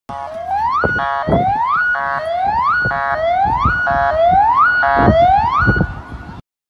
Paramount Eas Alarm (usa) Botão de Som
Sound Effects Soundboard9 views